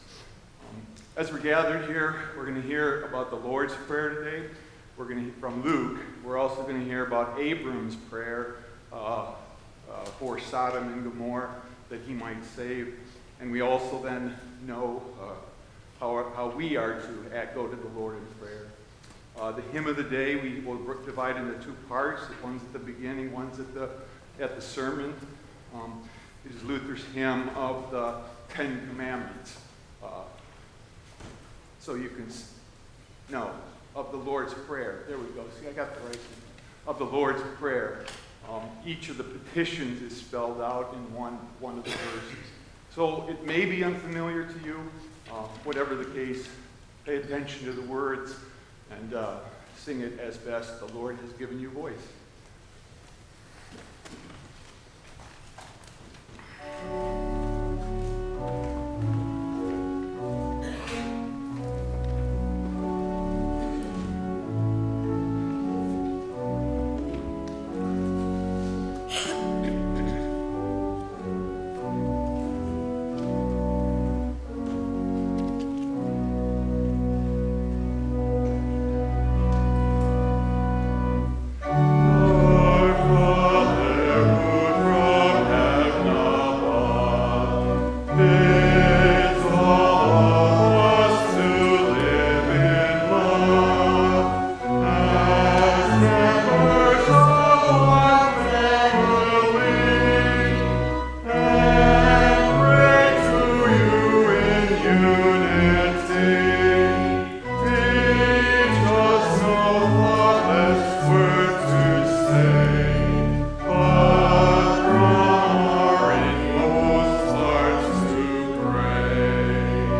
Permission to podcast/stream the music in this service obtained from ONE LICENSE with license A-717990.